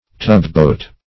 Tugboat \Tug"boat`\, n.